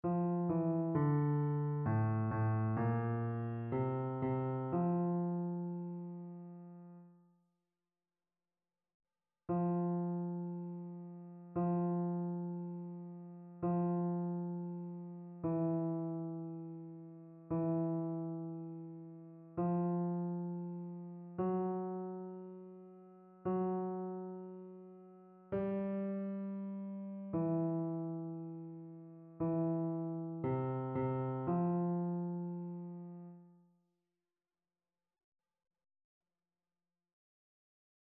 Basse
annee-a-temps-ordinaire-7e-dimanche-psaume-102-basse.mp3